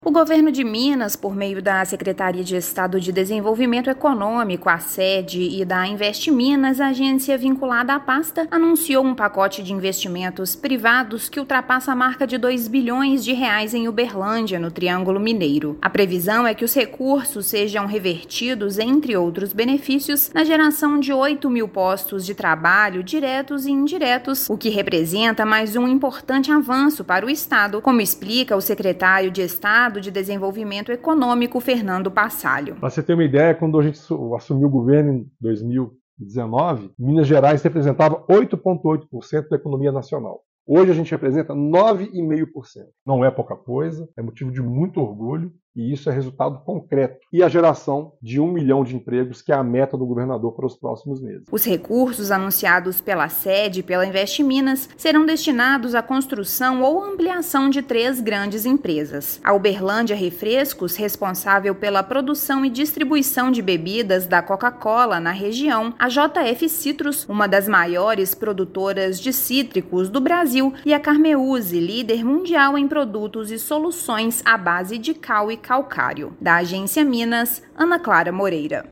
Iniciativas reforçam o compromisso do Estado e empresas da cidade com o desenvolvimento econômico da região do Triângulo Mineiro. Ouça matéria de rádio.